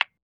Tap.wav